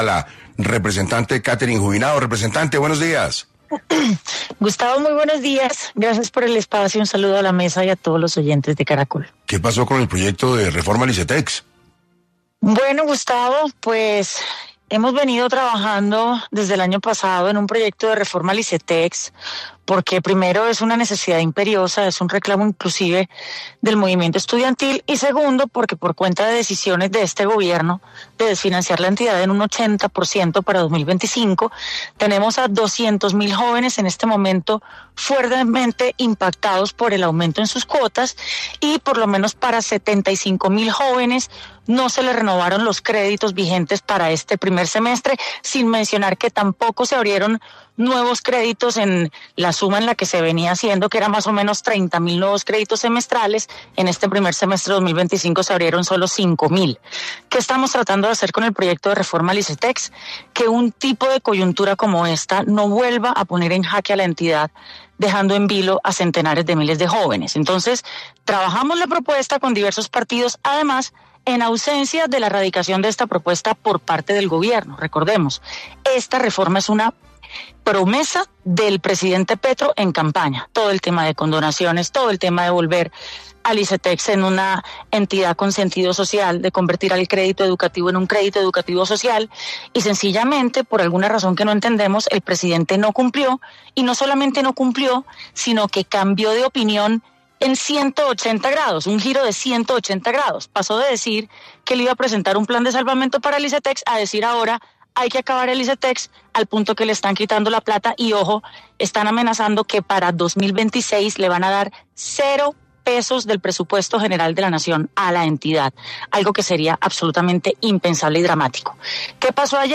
En entrevista con 6AM de Caracol Radio, la representante Catherine Juvinao denunció que la reforma al ICETEX está en riesgo por falta de apoyo del mismo Gobierno Nacional.